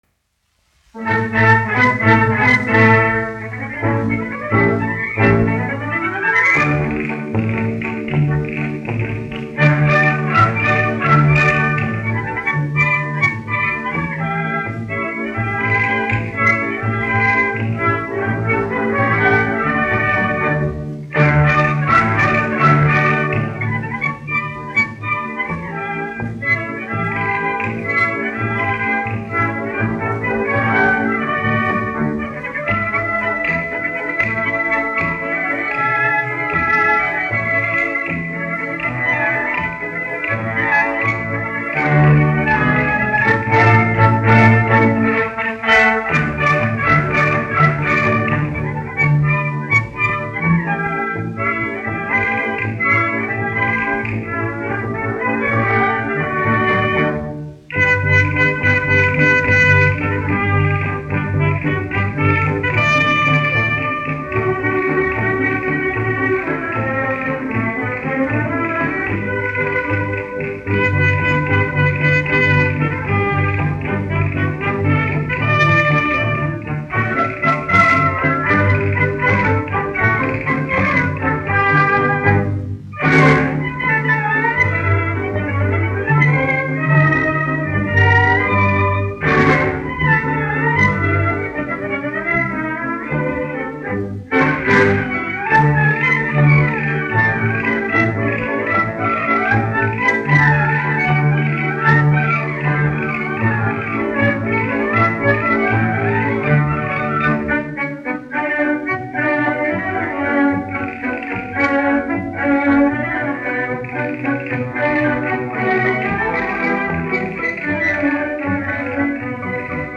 1 skpl. : analogs, 78 apgr/min, mono ; 25 cm
Valši
Orķestra mūzika
Latvijas vēsturiskie šellaka skaņuplašu ieraksti (Kolekcija)